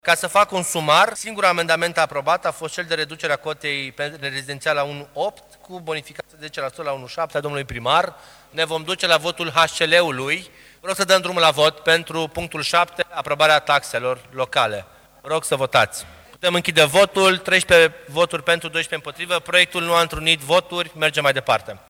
Președintele de ședință, viceprimarul Ruben Lațcău, după mai mult de două ore de discuții în plenul Consiliului Local.